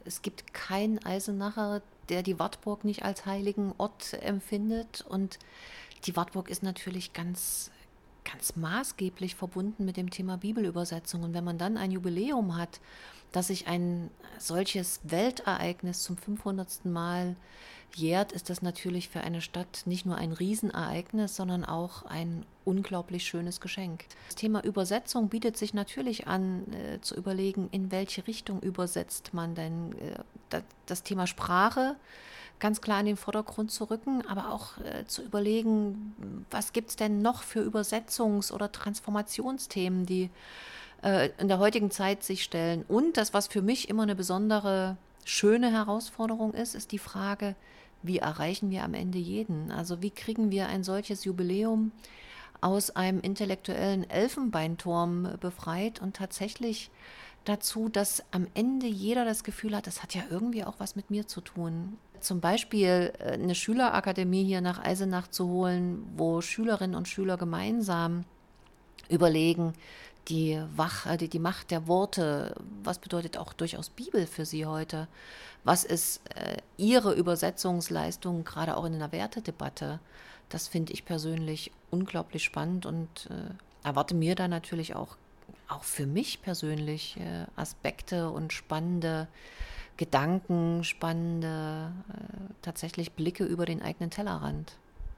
O-Ton_Oberbuergermeisterin_Katja_Wolf.mp3